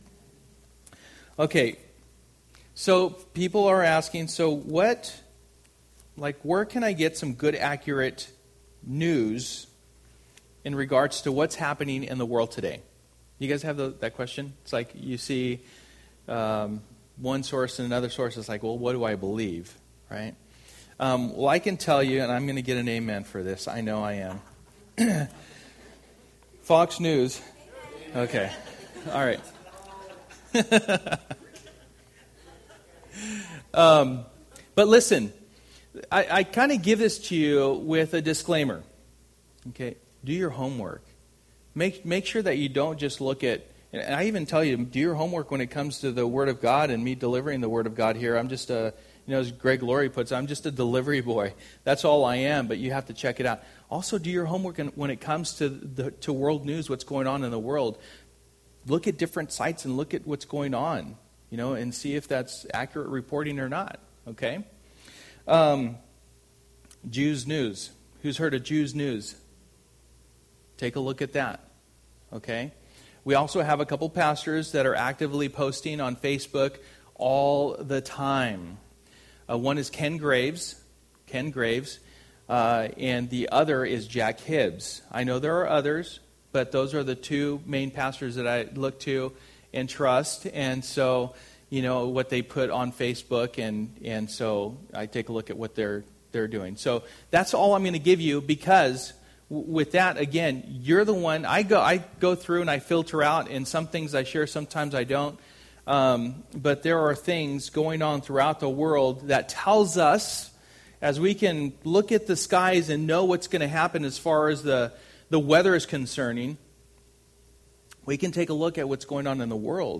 Model & Guard Passage: Titus 2:11-15 Service: Sunday Morning %todo_render% « Healthy Living Accoring to God Is it About Good Works or Not?!